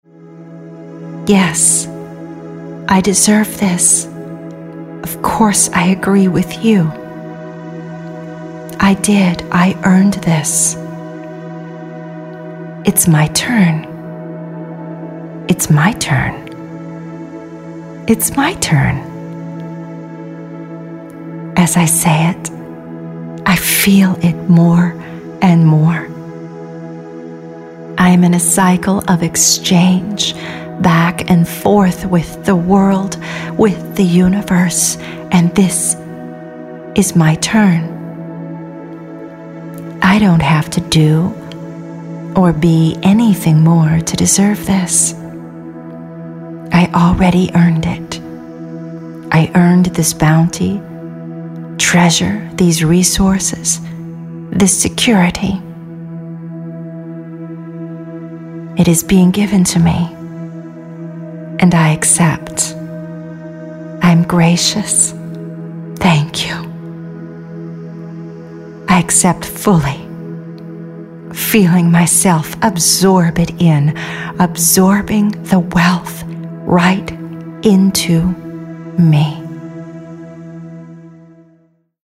Overnight Riches Meditation Playlist
1. Financial Abundance: A Flowdream Meditation